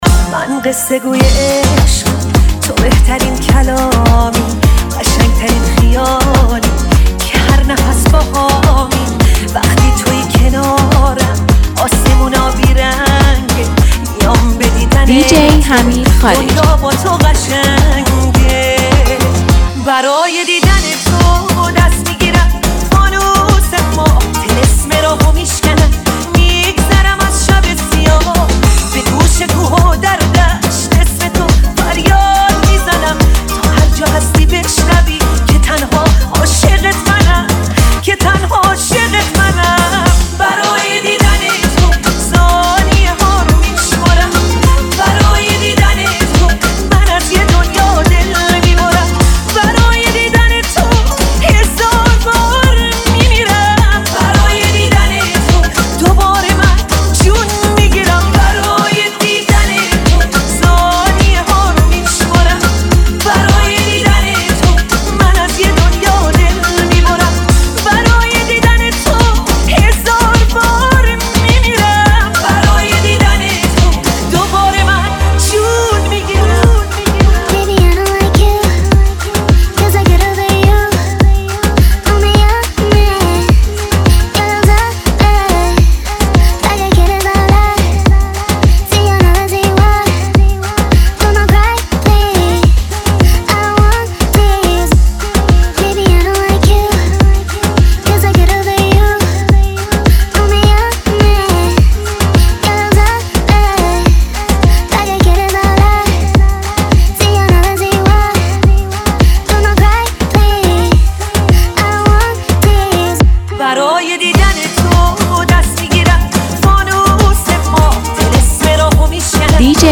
دانلود ریمیکس جدید آهنگ نوستالژی